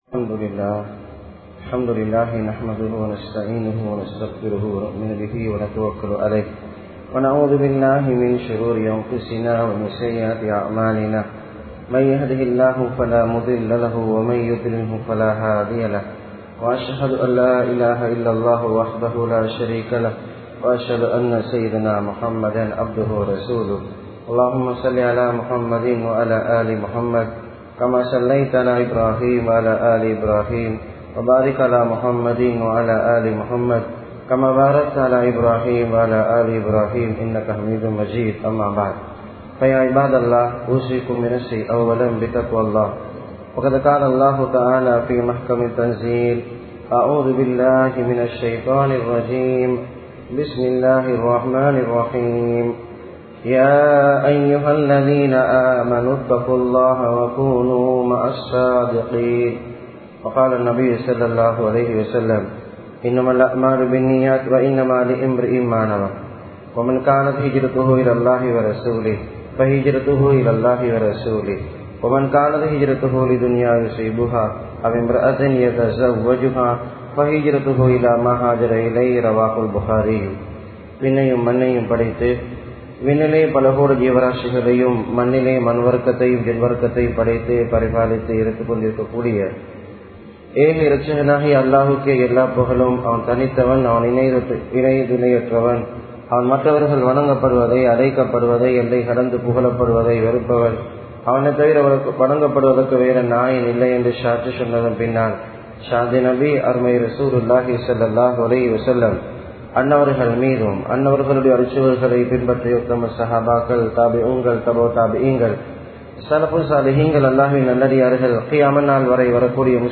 Rahasiyamana Amalhalum Suvarkamum (ரகசியமான அமல்களும் சுவர்க்கமும்) | Audio Bayans | All Ceylon Muslim Youth Community | Addalaichenai